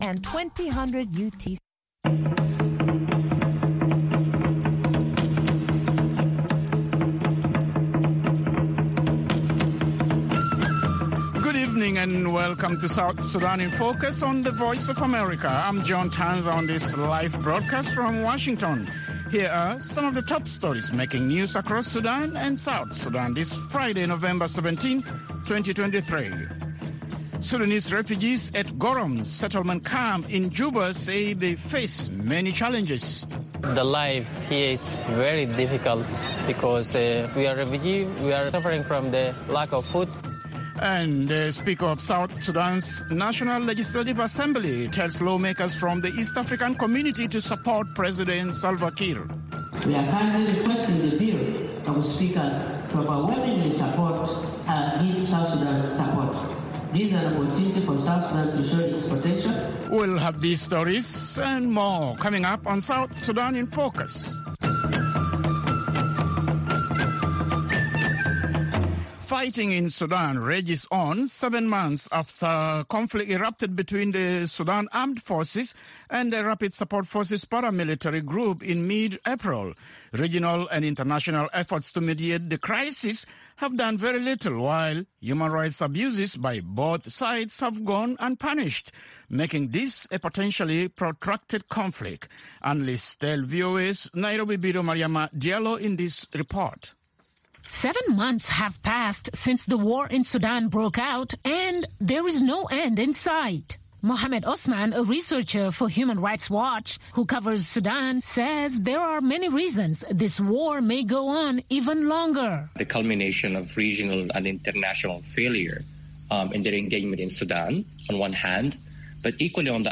and a network of reporters around South Sudan and in Washington.